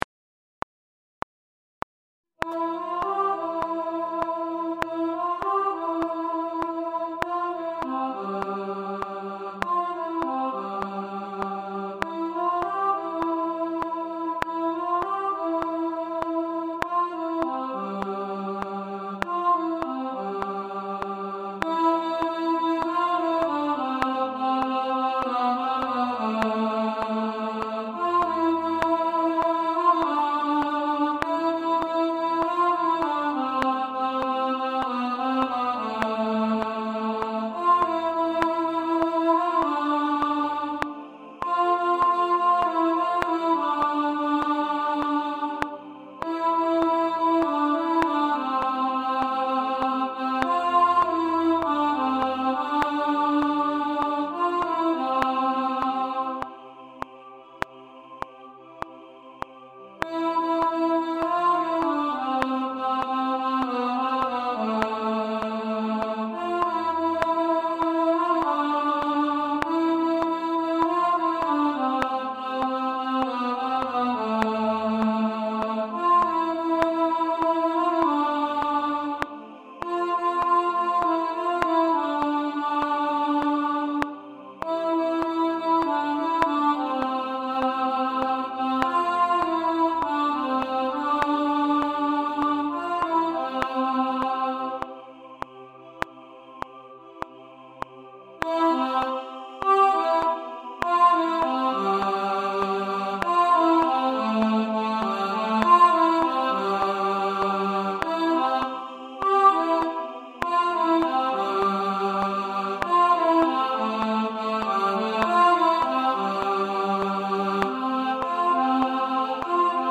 Only You – Alto 1 | Ipswich Hospital Community Choir